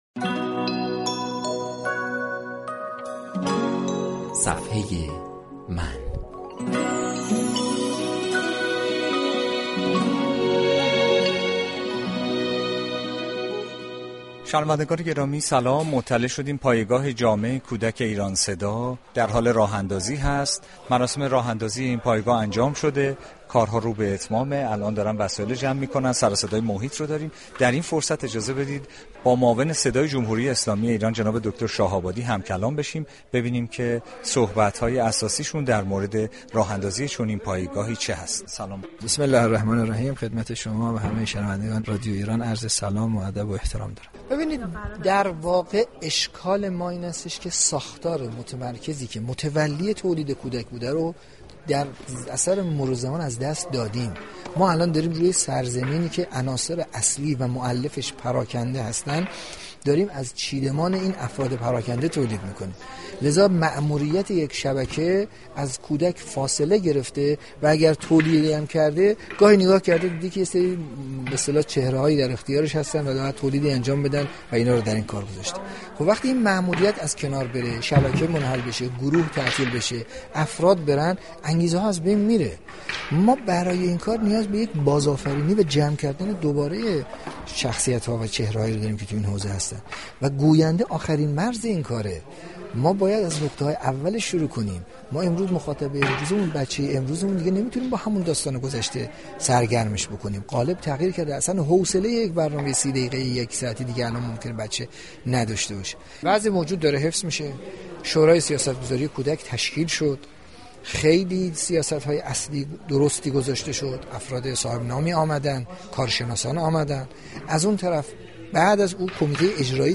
«دكتر حمید شاه آبادی» معاون صدای رسانه ملی درگفت و گو با بخش «صفحه من» رادیو ایران گفت